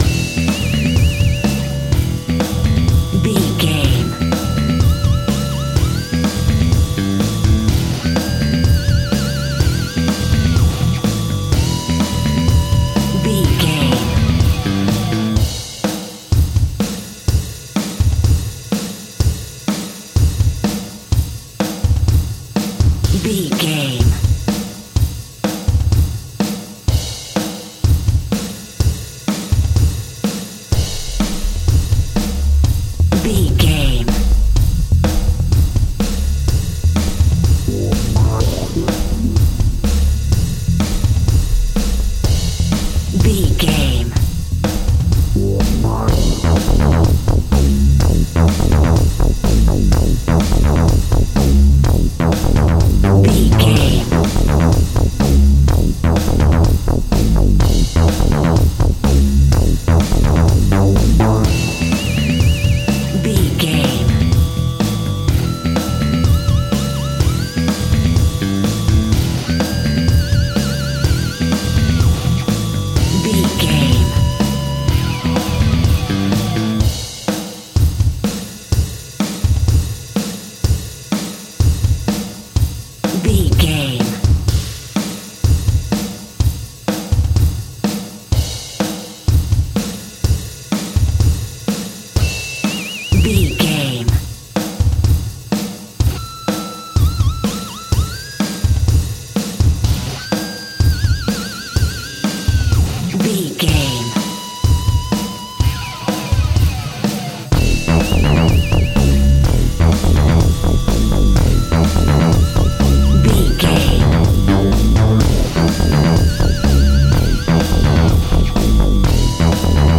Power Breakbeat Music.
Aeolian/Minor
Funk
hip hop
drums
bass guitar
electric guitar
piano
hammond organ
percussion